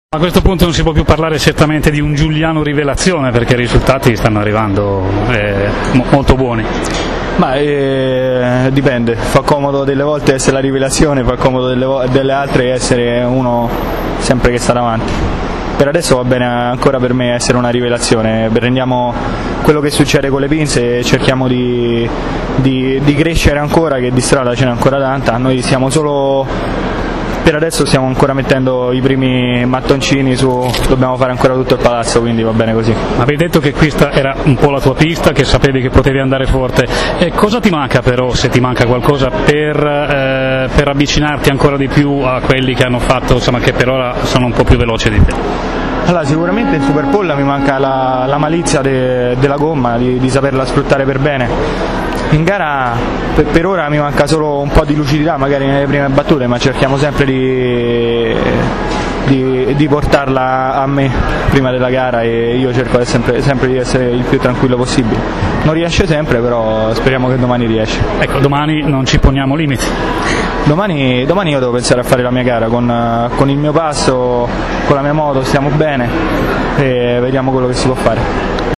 Cercavano vanamente di fare meglio di lui prima Rea, secondo con 1’35”991 e poi le due fantastiche sorprese di questa Superpole, Badovini (ascolta la sua intervista) e Giugliano (